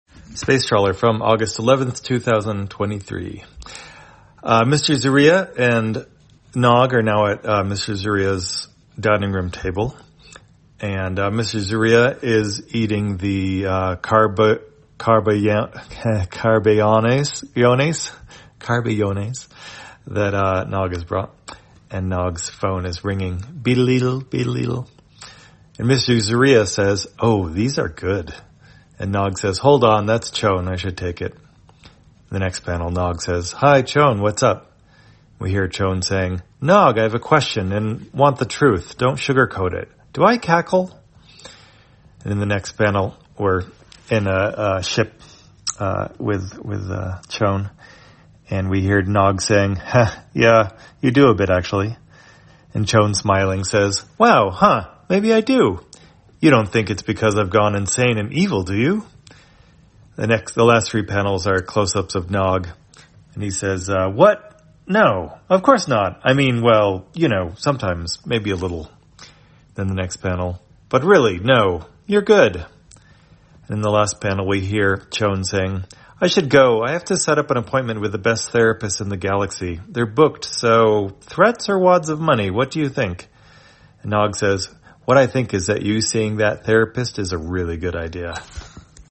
Spacetrawler, audio version For the blind or visually impaired, August 11, 2023.